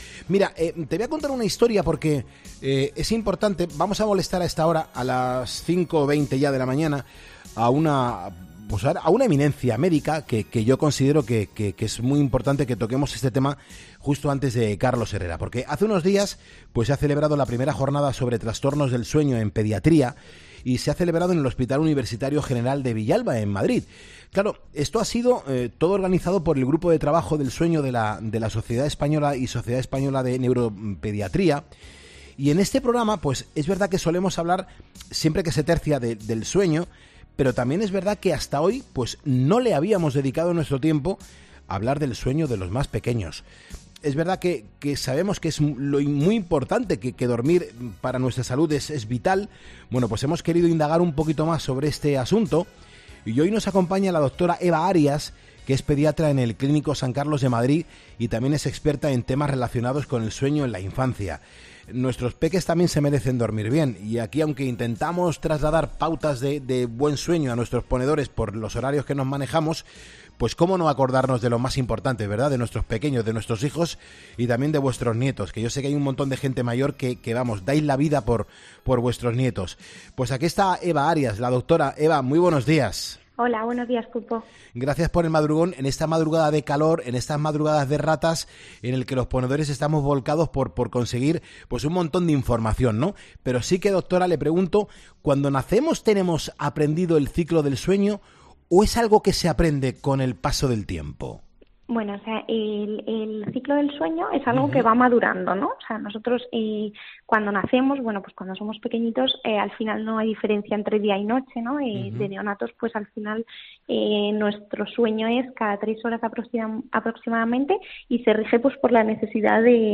habla con la doctora